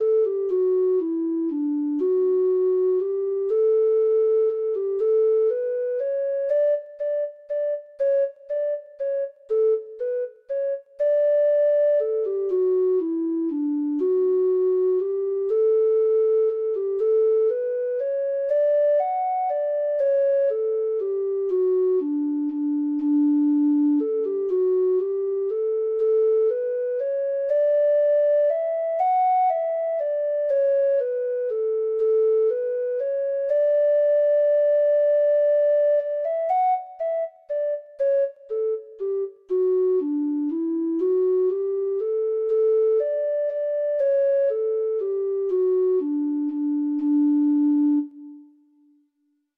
Traditional Music of unknown author.
Irish